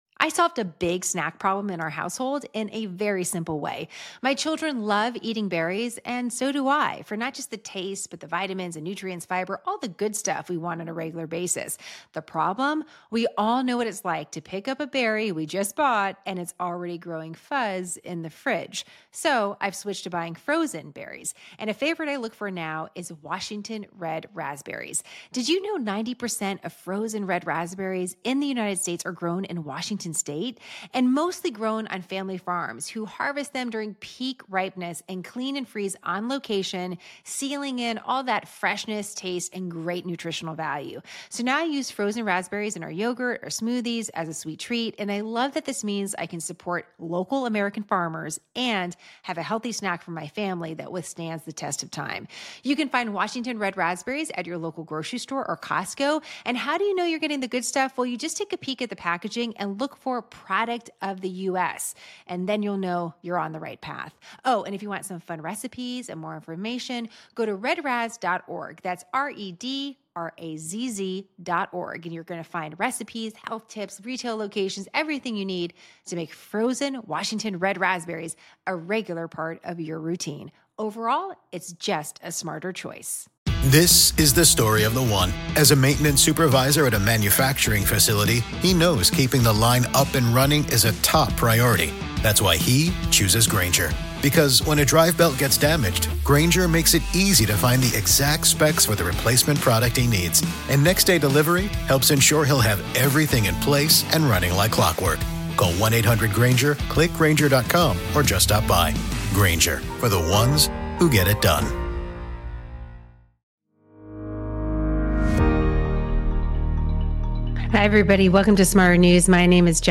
A sampling of soundbites from political commentators and politicians.